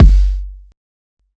Kicks
Kick (32).wav